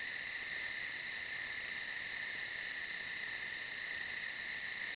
1 channel
crckts02.wav